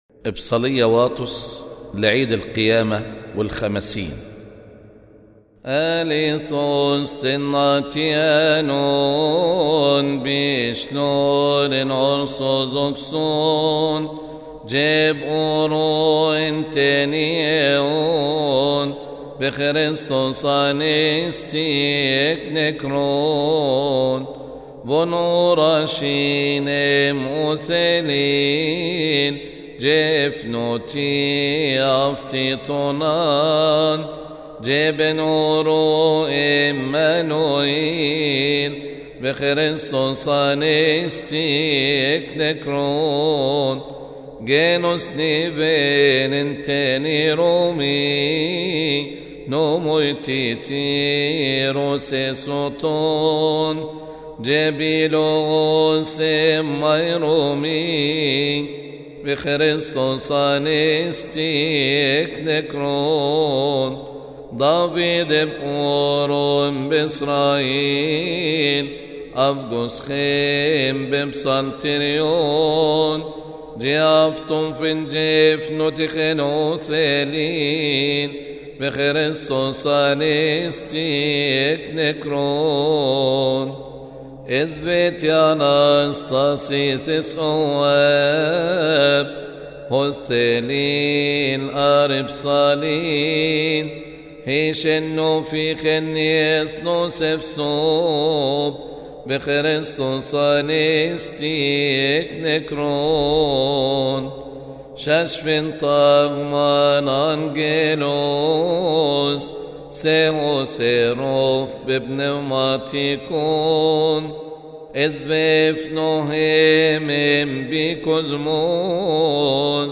أبصالية واطس لعيد القيامة والخمسين